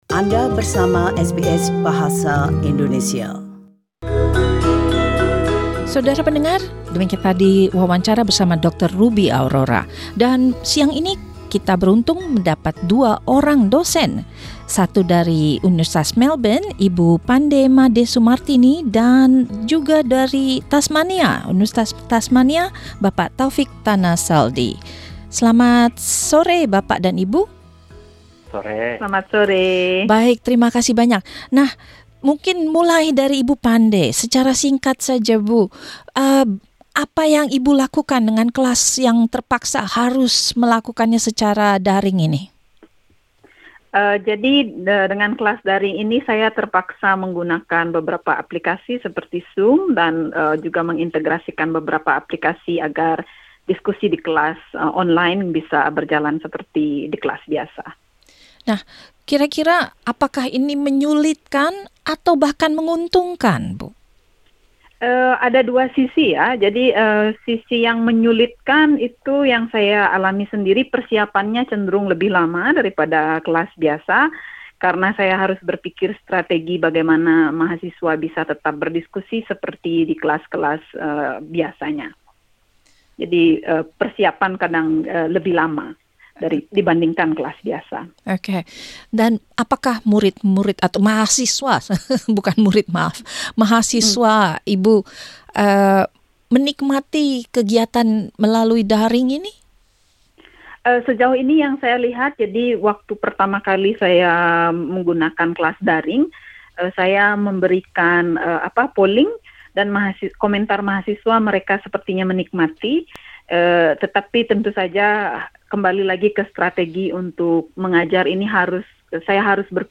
Dua orang dosen pengajar Bahasa Indonesia menjelaskan sistem yang diterapkan di universitas masing-masing.